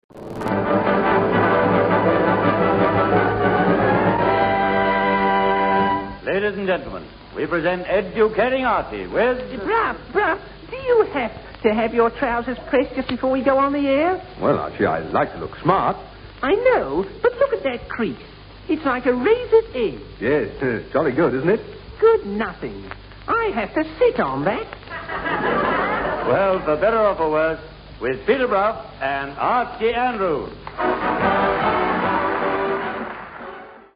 'Educating Archie' was a much-loved BBC Comedy show which ran from 1950 to 1958. The programme was probably the most unlikely radio success story ever, given it starred a ventriloquist and his doll.